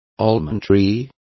Also find out how almendro is pronounced correctly.